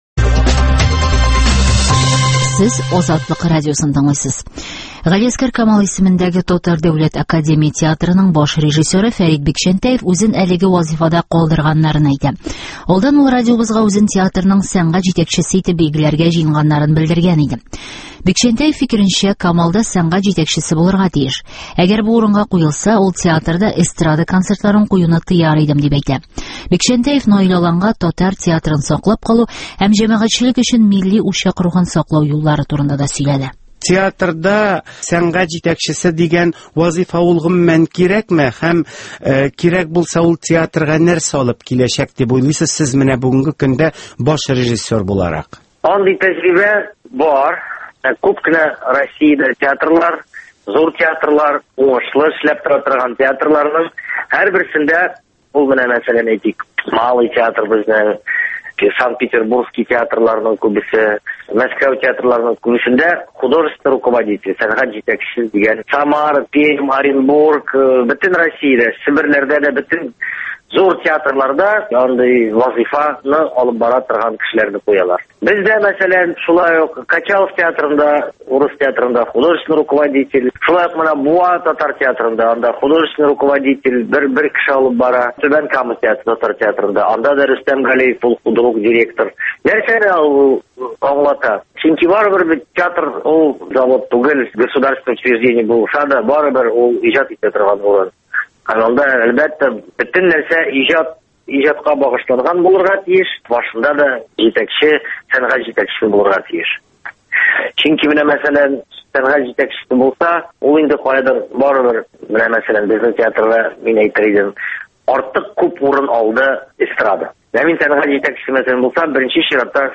Фәрит Бикчәнтәев белән әңгәмә